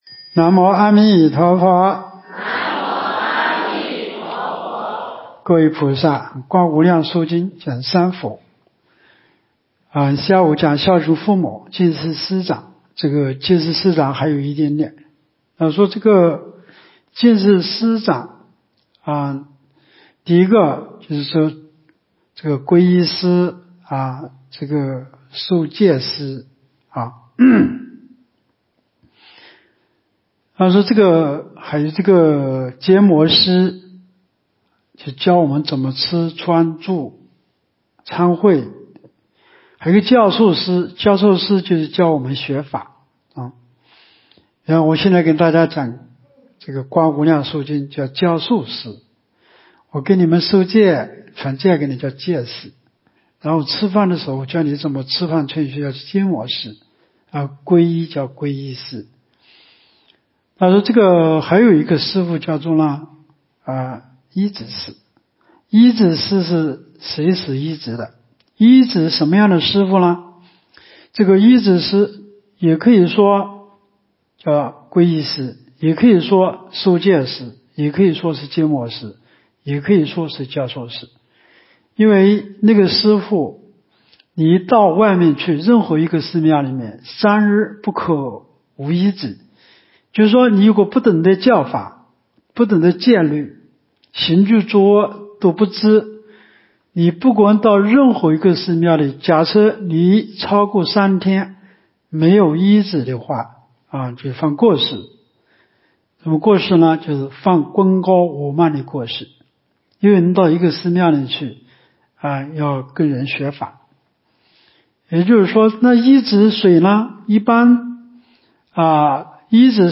2024冬季佛七开示（十二）（观经）
无量寿寺冬季极乐法会精进佛七开示（12）（观无量寿佛经）...